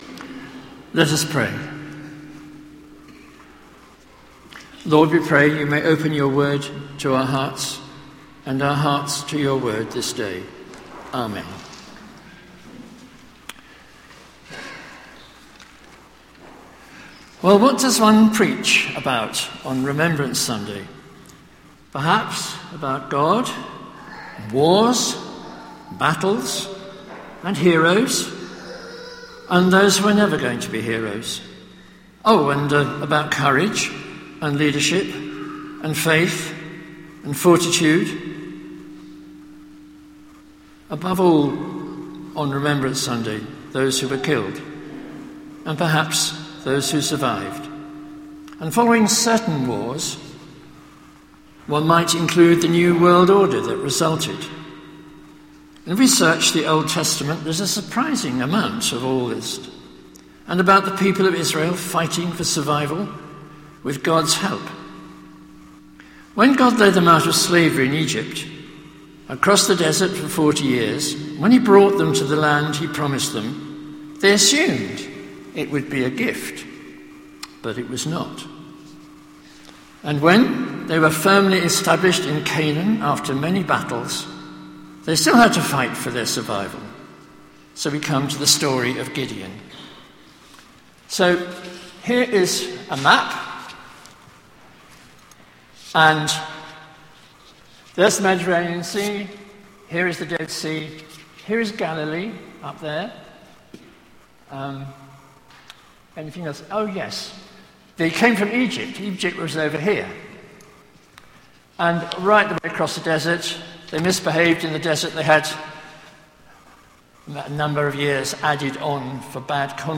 Remembrance Sunday 2019